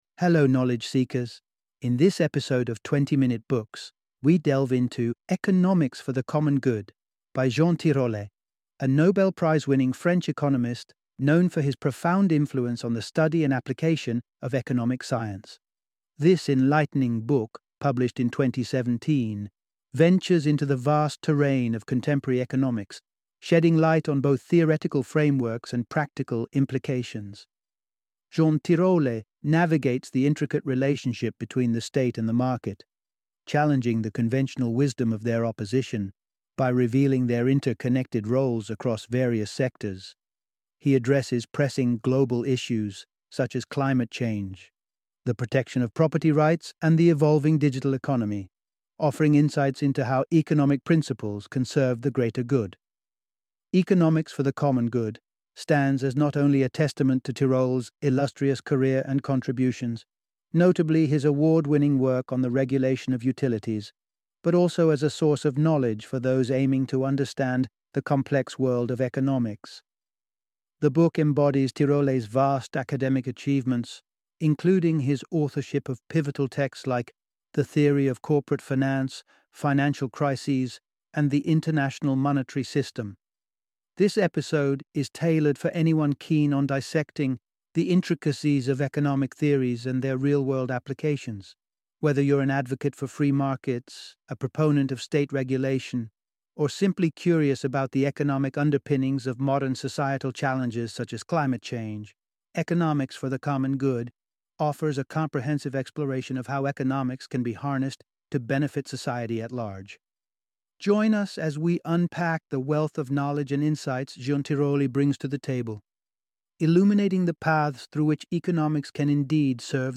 Economics for the Common Good - Audiobook Summary